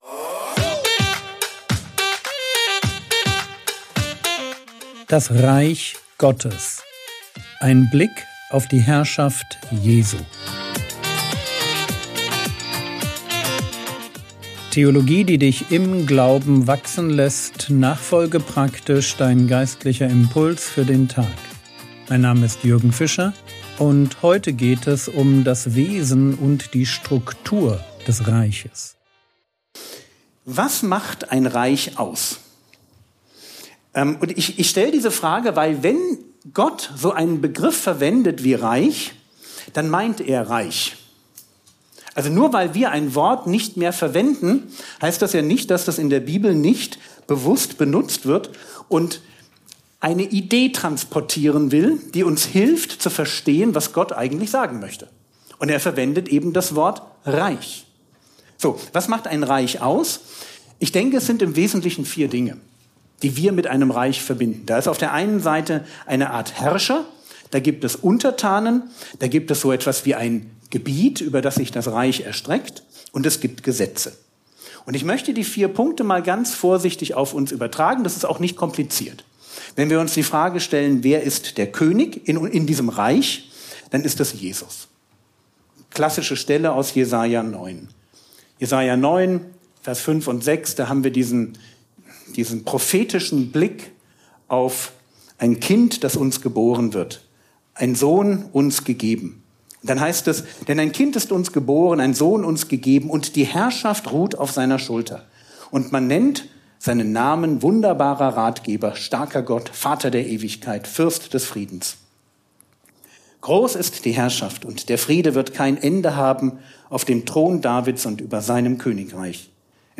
Vortrag Paderborn